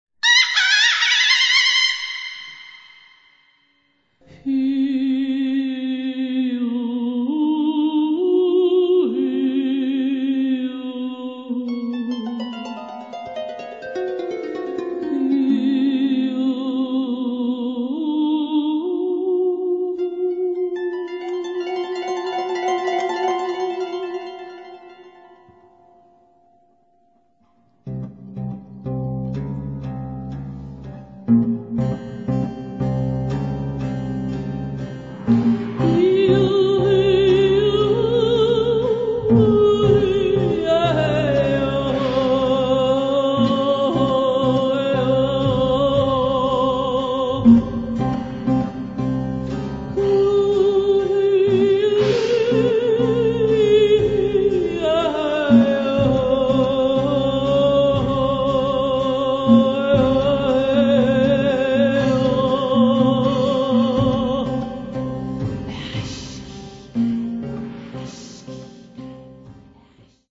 Konzertharfe und keltische Harfe